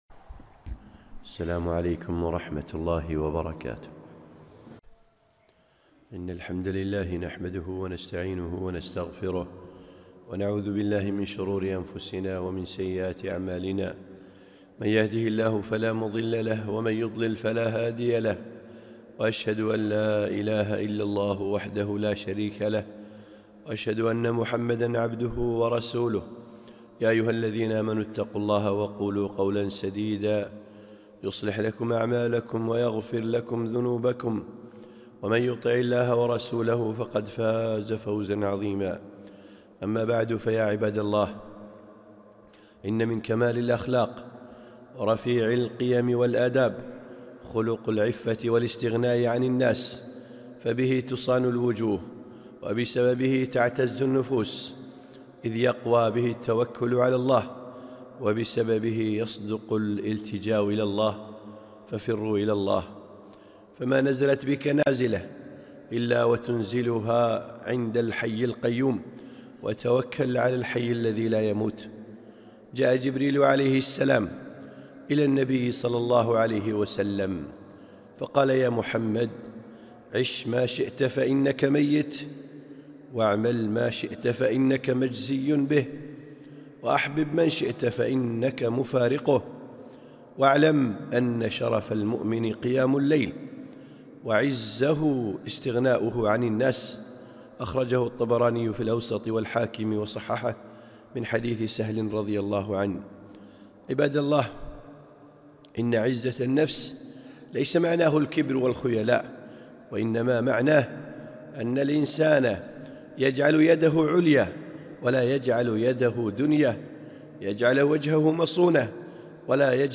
خطبة - الاستغناء عن الناس